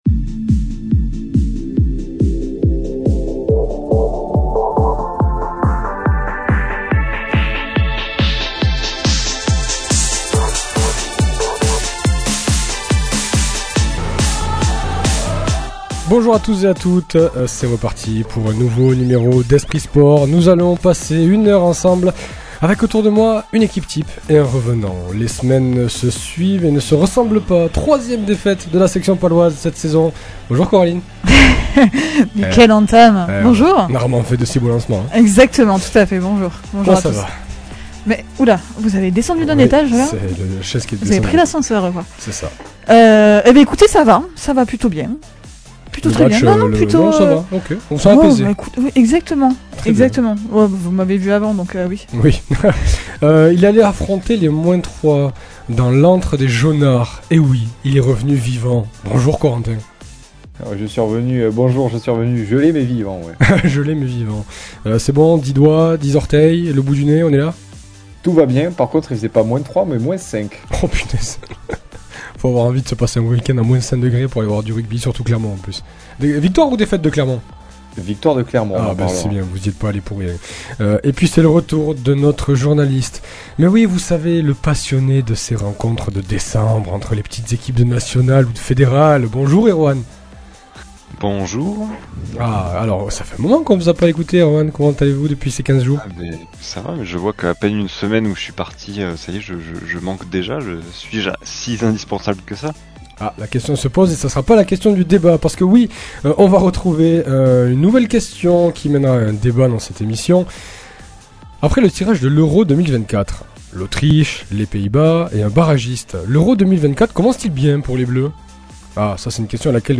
C’est également l’occasion de faire un peu d’histoire dans cet entretien aussi complet que passionnant.